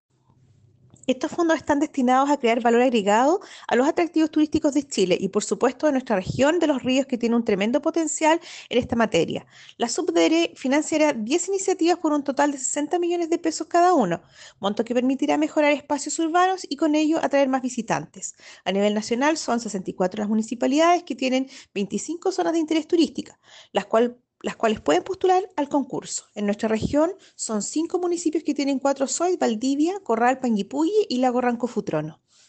Seremi-de-Economia-por-Fondo-PMU-ZOIT-okkkk.mp3